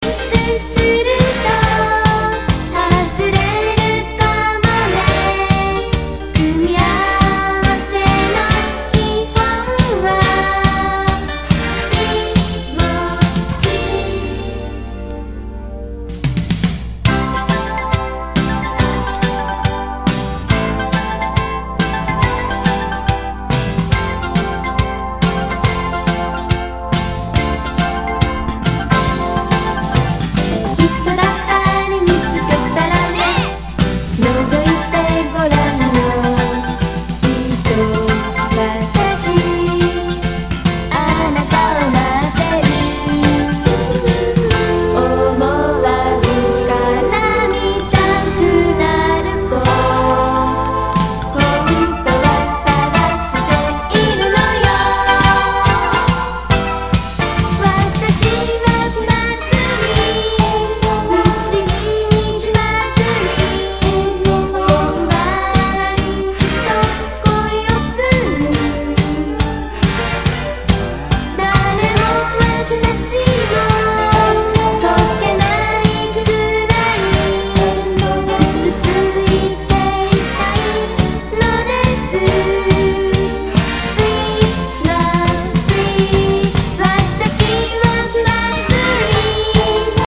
バブルガム系のグルーヴィーポップス!!